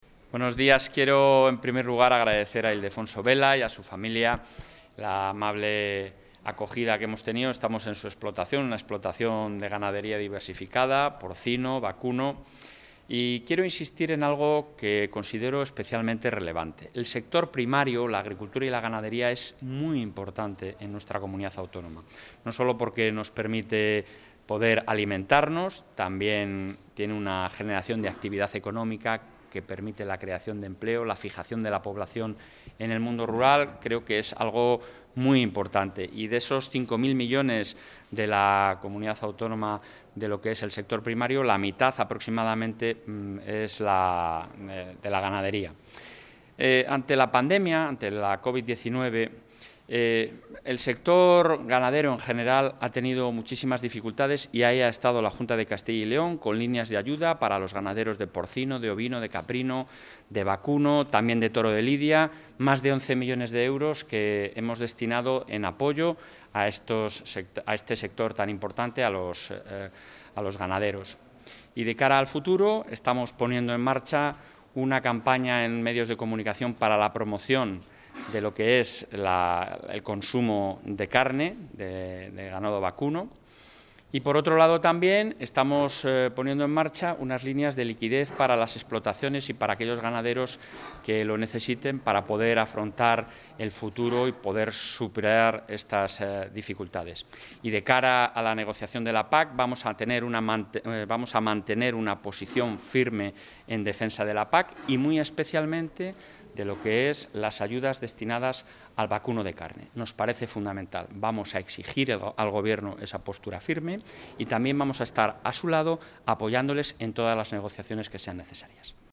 Declaraciones del presidente de la Junta de Castilla y León.